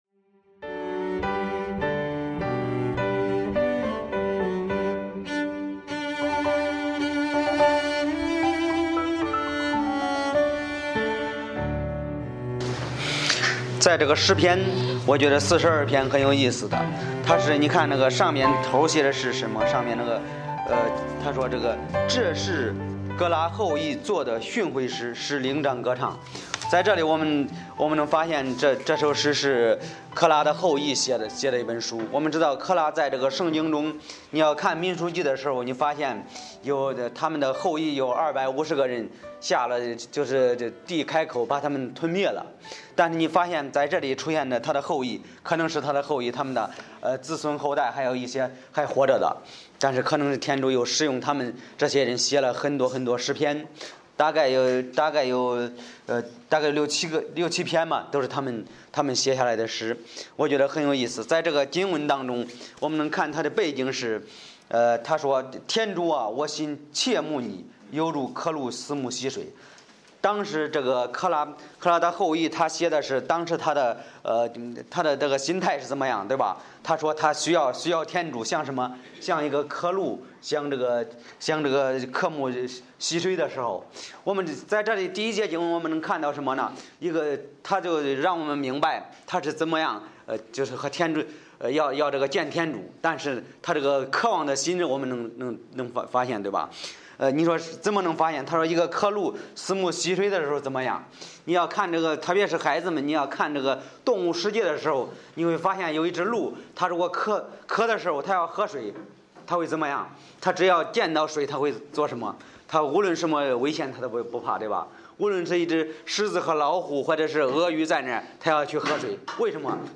Series: 周日礼拜